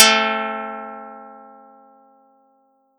Audacity_pluck_4_14.wav